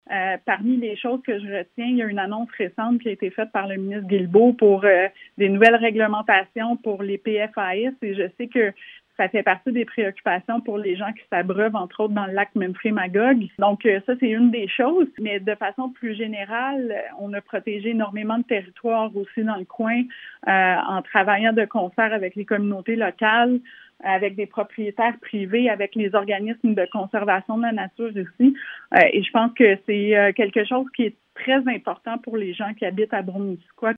En entrevue avec M105 plus tôt vendredi, elle est revenue sur cette période qu’elle a qualifiée de tumultueuse, notamment avec la pandémie de Covid-19, la guerre en Ukraine et récemment la guerre commerciale avec les États-Unis.